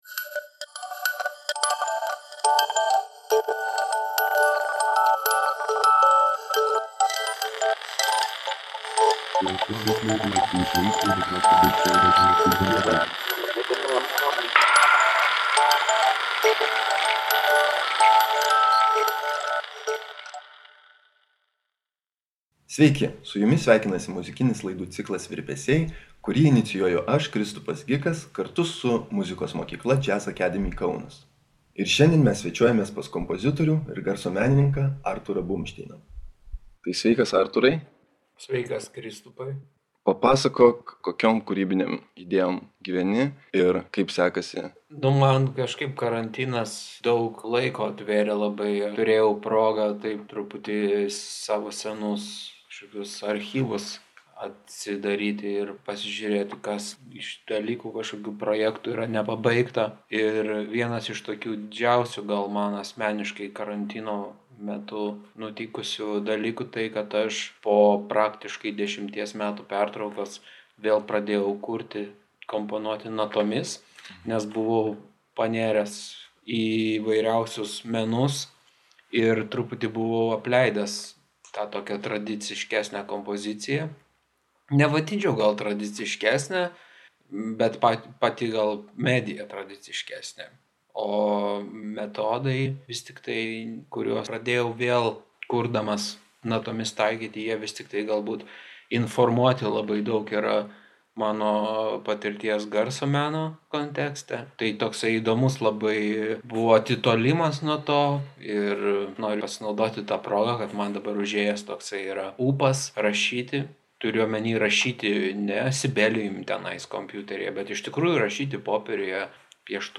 Podcast’e panaudoti žemiau nurodytų autorių muzikos fragmentai, gavus jų sutikimą.